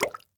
Minecraft Version Minecraft Version 25w18a Latest Release | Latest Snapshot 25w18a / assets / minecraft / sounds / block / bubble_column / bubble1.ogg Compare With Compare With Latest Release | Latest Snapshot
bubble1.ogg